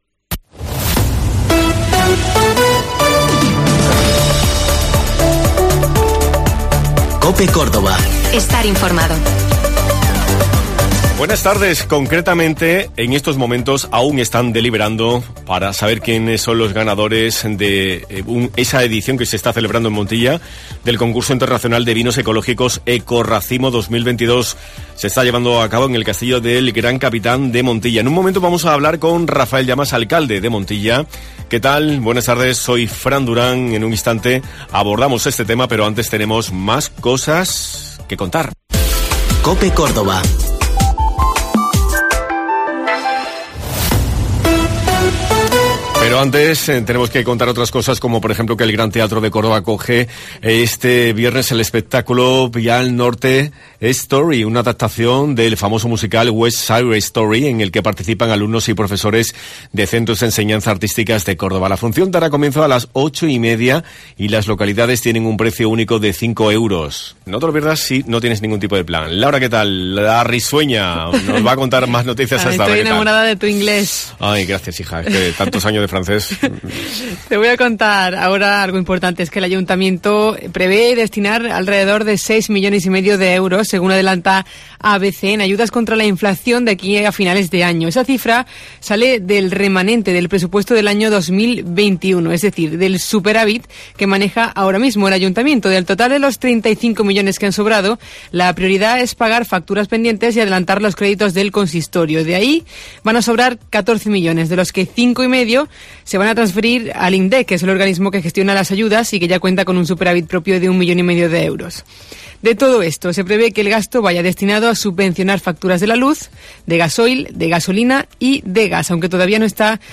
El Castillo del Gran Capitán de Montilla (Córdoba) acoge este viernes la XXIII edición del Concurso Internacional de Vinos Ecológicos Ecoracimo 2022 "convirtiendo a Montilla, durante estos días, en la capital de los vinos ecológicos de España. Hemos hablado con Rafael Llamas, alcalde de Montilla, que nos ha contado en qué están trabajando con vistas a próximas ediciones.